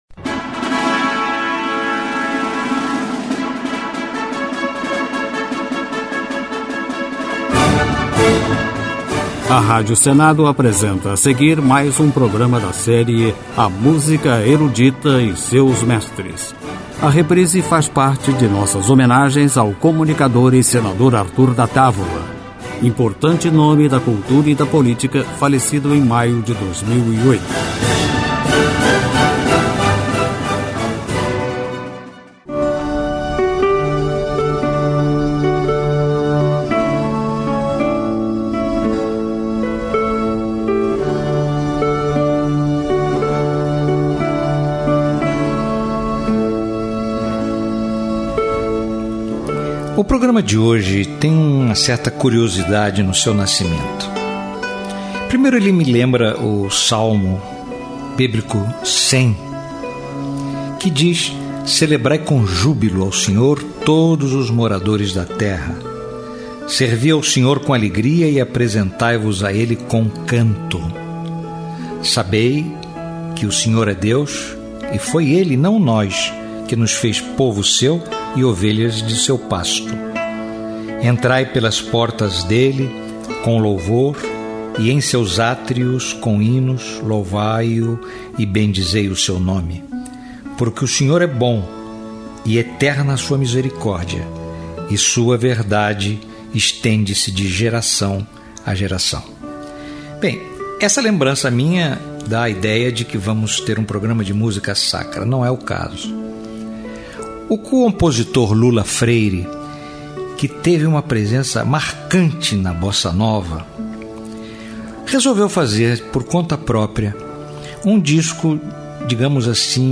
Música Erudita
Pós-romantismo Impressionismo